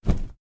Flap Wing 1.wav